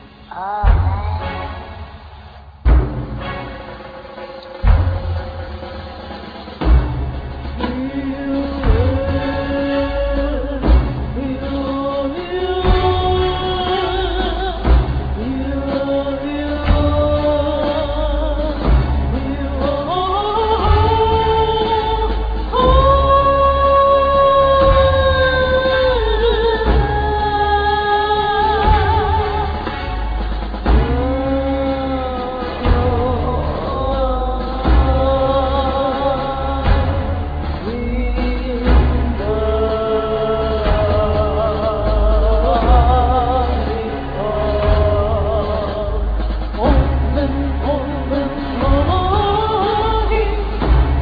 Keyboards, sing, percussions, flutes
Percussions,narration
Violin